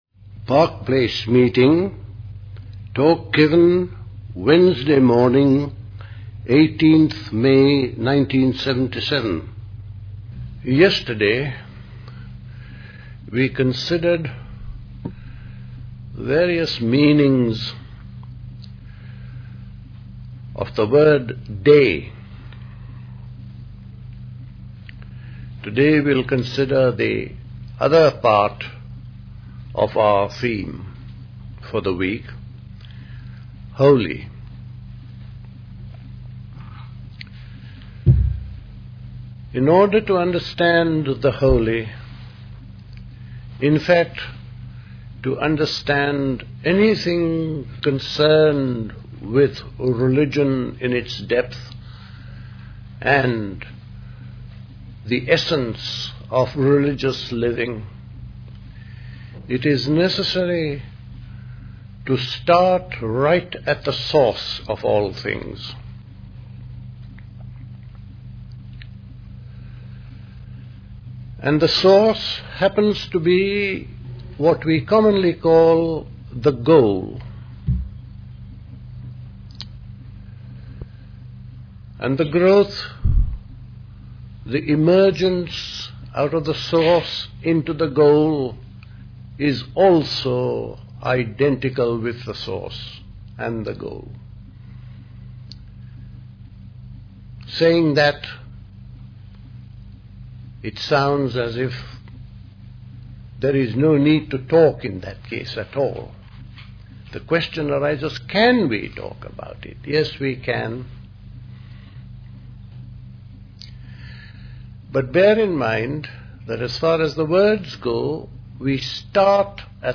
at Park Place Pastoral Centre, Wickham, Hampshire on 18th May 1977